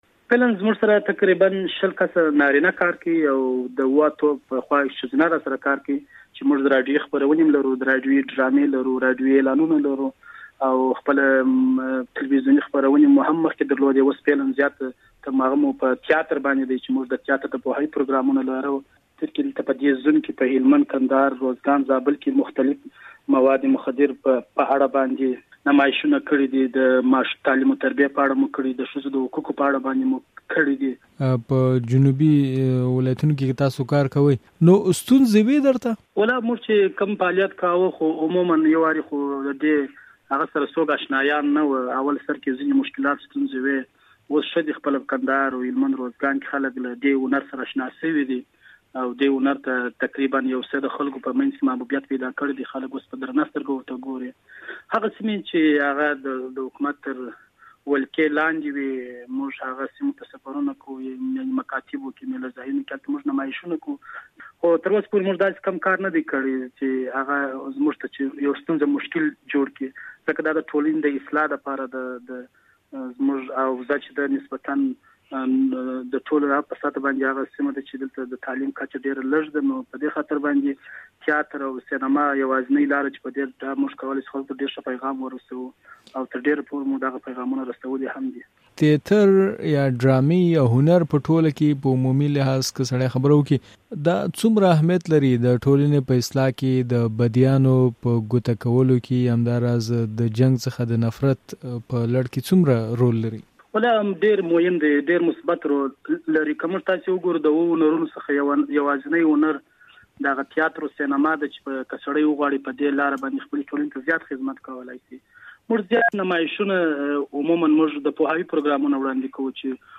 د مشال ريډيو د سولې پر لور خپرونې لپاره مرکه وکړه